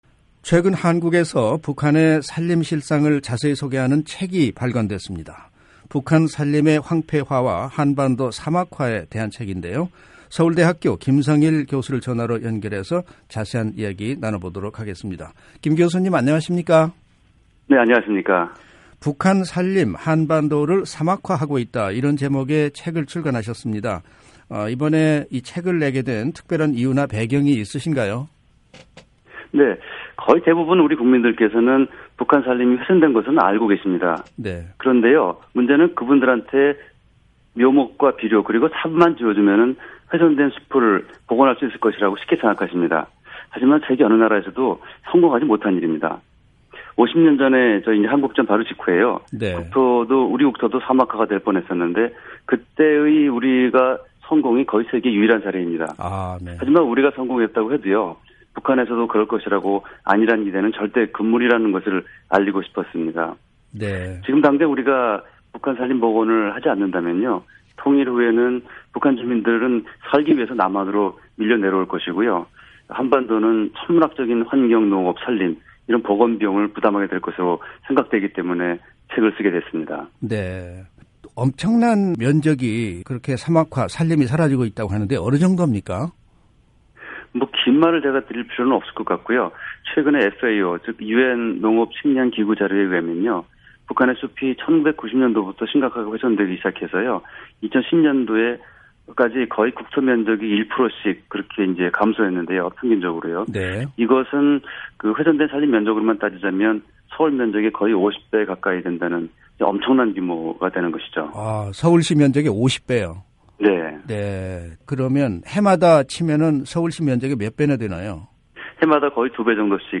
[인터뷰